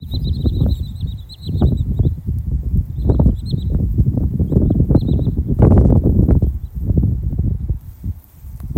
Birds -> Waders ->
Wood Sandpiper, Tringa glareola